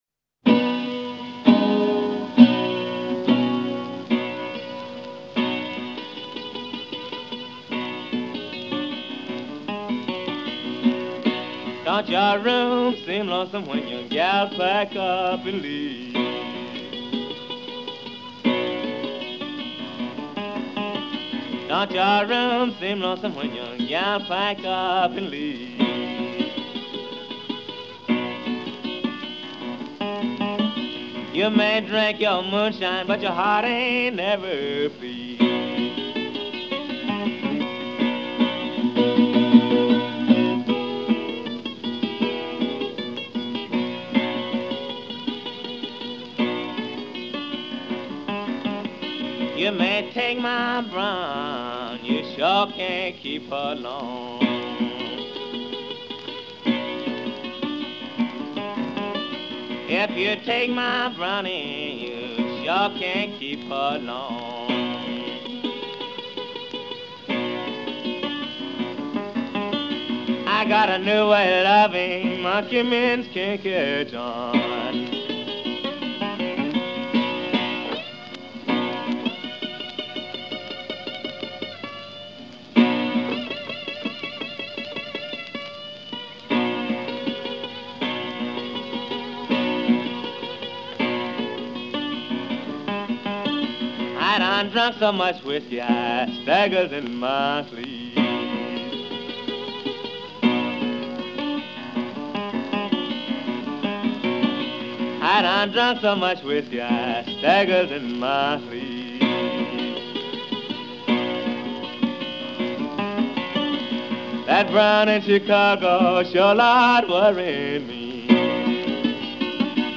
Early 1920s-30s guitar Blues.